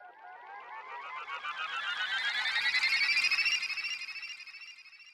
FX [ Chop ].wav